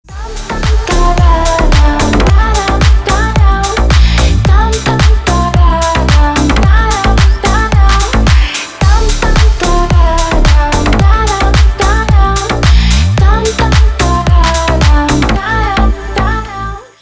громкие
deep house
женский голос
Electronic
Стиль: deep house